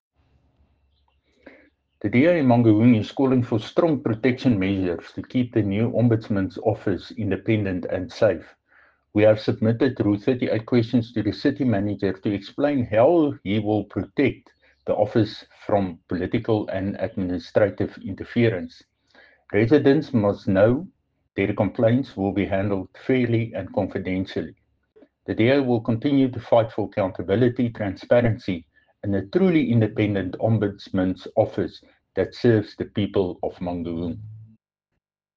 Afrikaans soundbites by Cllr Dirk Kotze and Sesotho soundbite by Jafta Mokoena MPL.